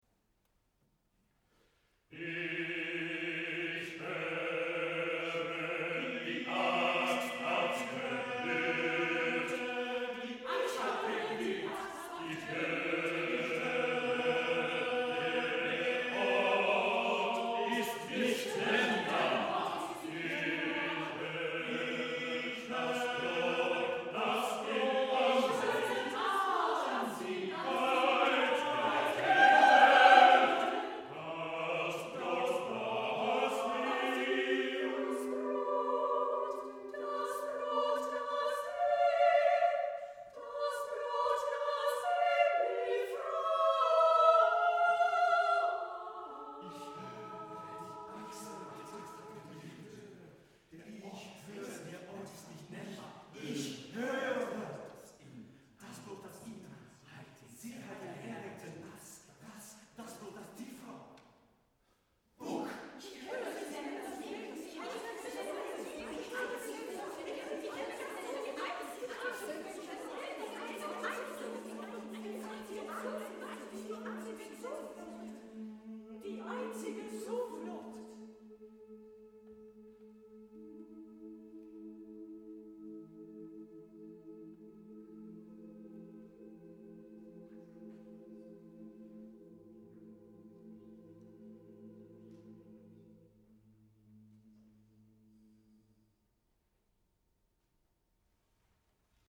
SECHS CHORSTUCKE
SATB
Live recording.